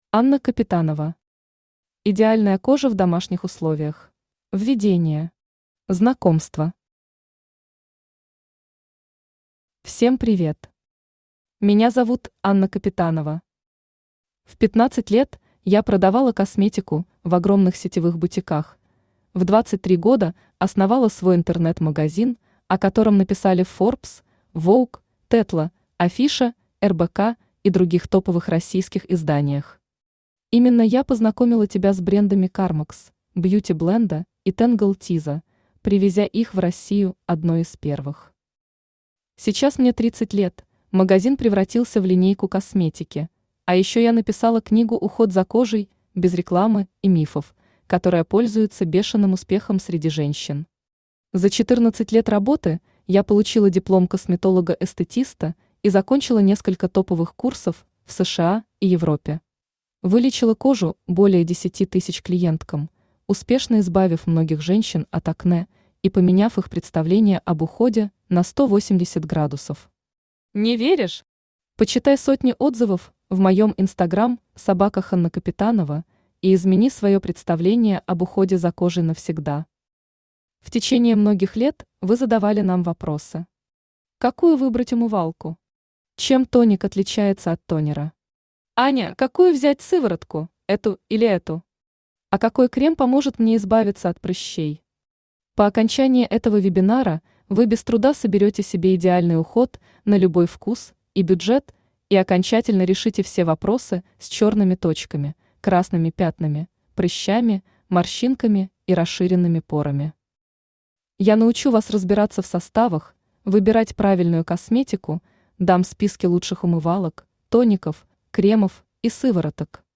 Аудиокнига Идеальная кожа в домашних условиях | Библиотека аудиокниг
Aудиокнига Идеальная кожа в домашних условиях Автор Анна Капитанова Читает аудиокнигу Авточтец ЛитРес.